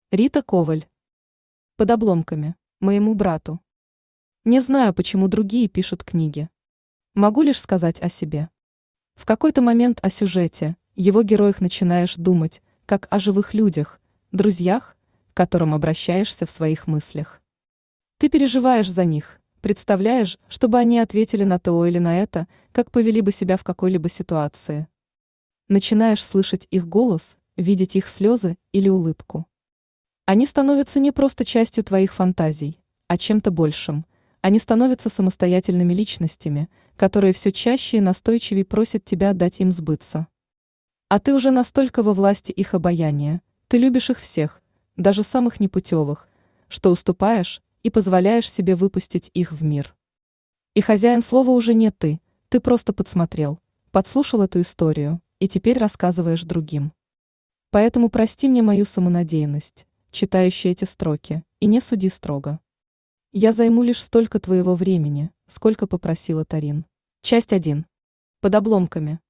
Aудиокнига Под обломками Автор Рита Коваль Читает аудиокнигу Авточтец ЛитРес. Прослушать и бесплатно скачать фрагмент аудиокниги